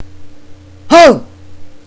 So in my vast amount free time, i have recorded myself saying a number of things retardely.